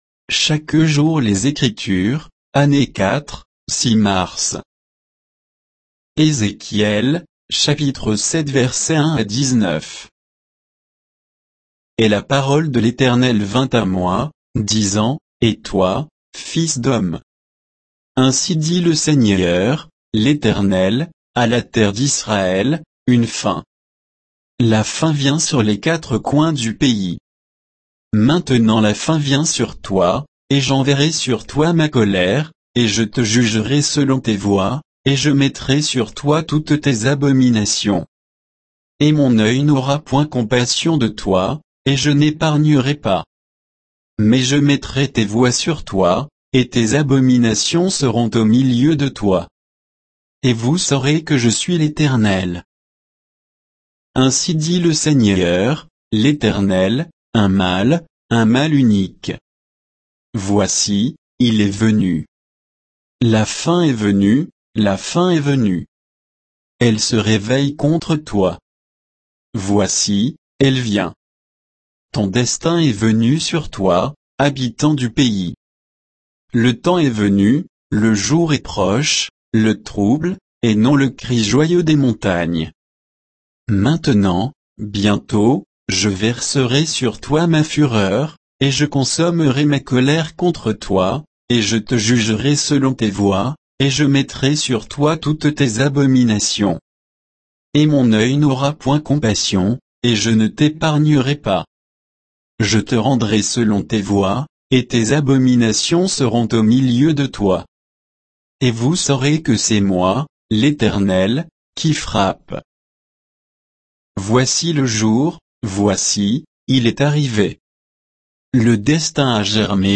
Méditation quoditienne de Chaque jour les Écritures sur Ézéchiel 7, 1 à 19